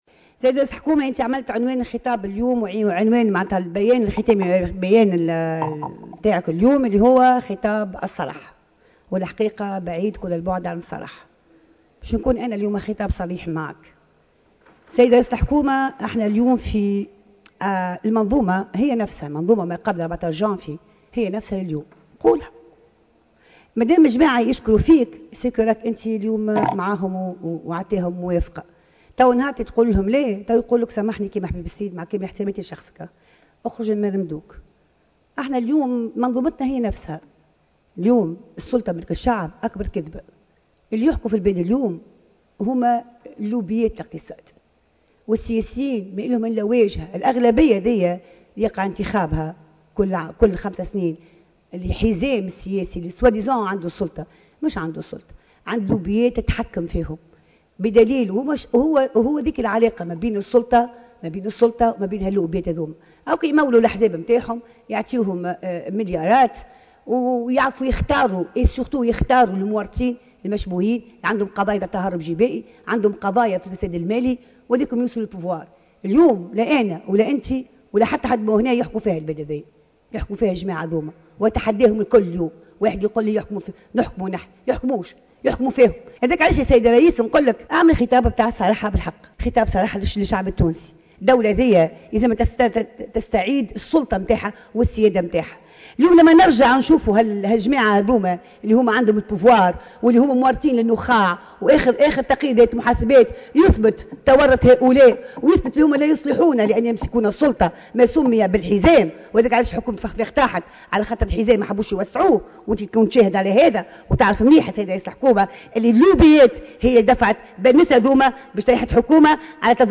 قالت النائب سامية عبوّ في مداخلة لها خلال الجلسة العامة المخصصة للنظر في مشروع ميزانية الدولة ومشروع الميزان الإقتصادي ومشروع قانون المالية لسنة 2021، "إنّ شعار "السلطة مِلك الشعب"، أكبر كذبة"، ولوبيات الاقتصاد هي التي تحكم اليوم في البلاد، محذرة رئيس الحكومة هشام المشيشي، من مواجهة مصير رئيس الحكومة الأسبق الحبيب الصيد.